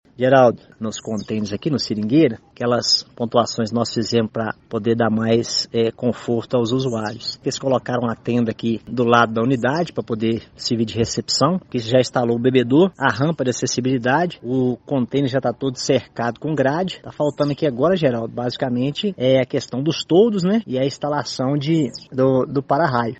A boa notícia é que o processo avançou e neste domingo, 12 de março, o vereador Leandro Guimarães Vieira (PTB) confirmou ao Portal GRNEWS que a tenda, bebedouro, rampa de acessibilidade e grades de cercamento já foram instalados na unidade modular proporcionando comodidade aos usuários e servidores. Avalia que ainda faltam os toldos e instalação de para-raios: